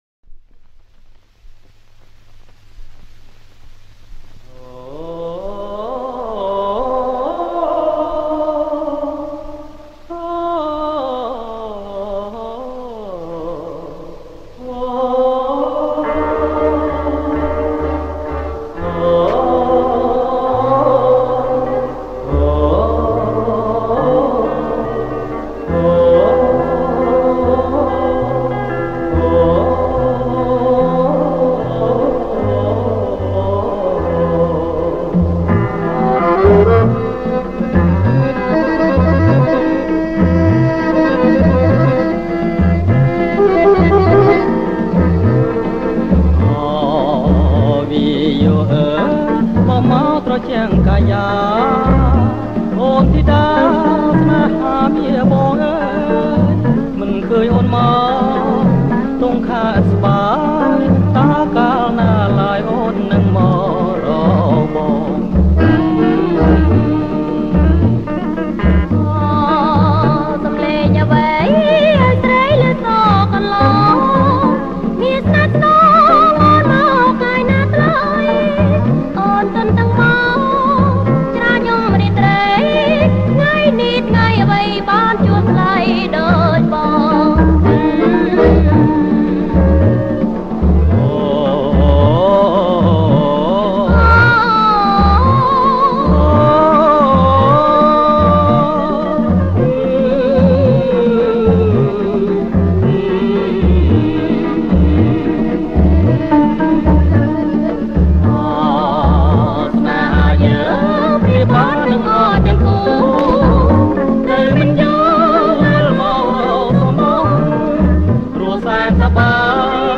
ប្រគំជាចង្វាក់ Rumba